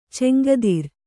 ♪ cemgadir